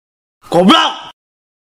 Efek suara Goblok!
Kategori: Suara viral